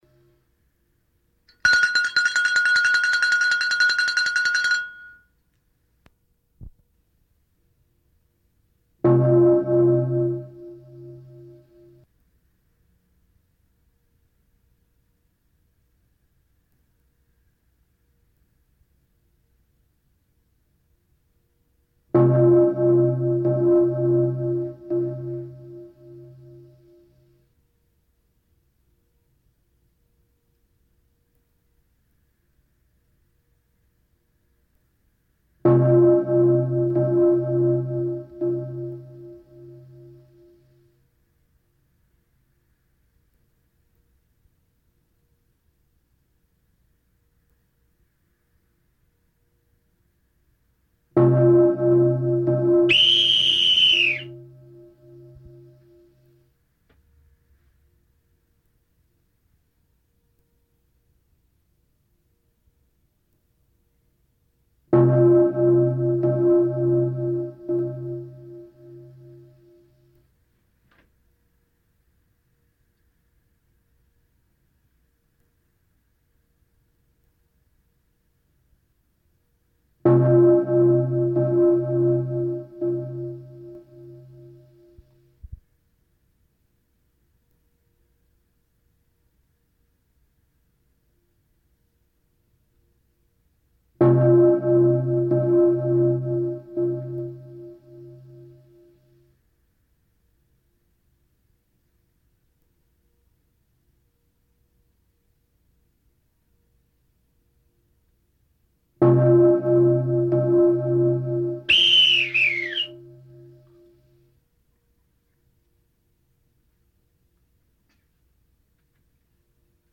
BELLS
BELLS Download mp3 of bells, used to commemorate those who've suffered abuse.
bells-clotheslineproject.mp3